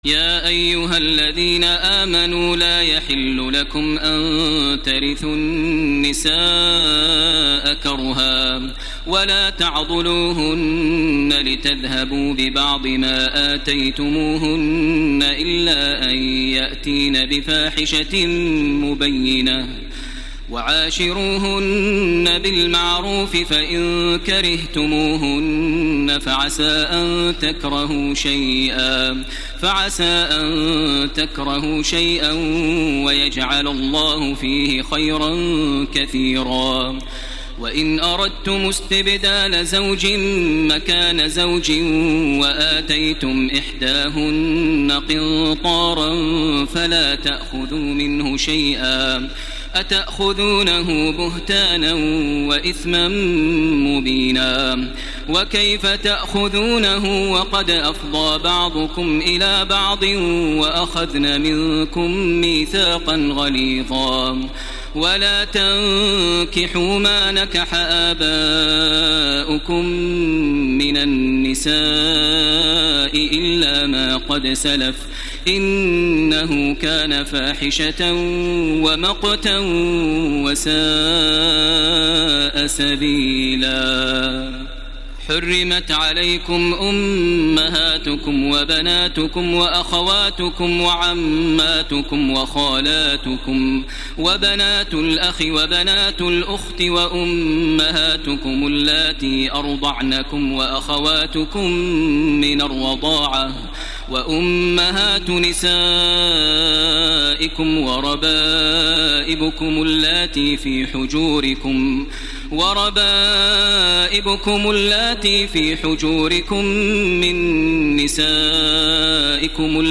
الليلة الرابعة من سورة النساء 19-87 > تراويح ١٤٣٤ > التراويح - تلاوات ماهر المعيقلي